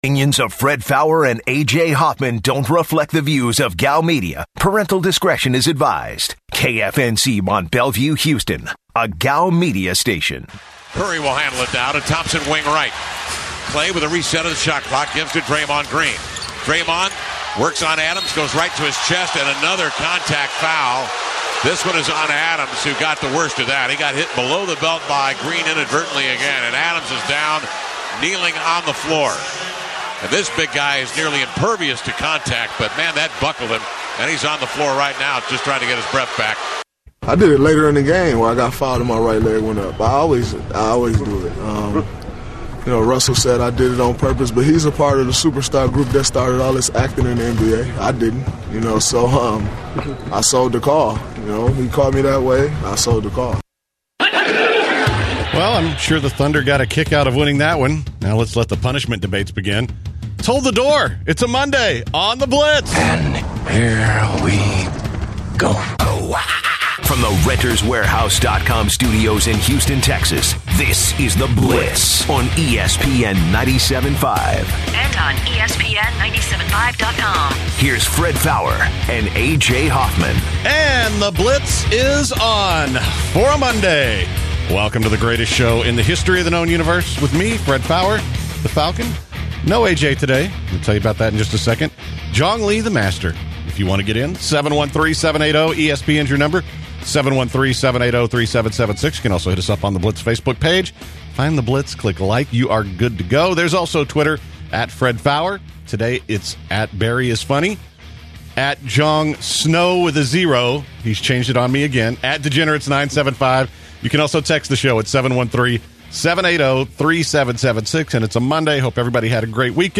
The guys take some calls regarding Houston Astros pitcher Dallas Keuchel, the future of the Houston Astros, the season outlooks for the Houston Texans and Dallas Cowboys.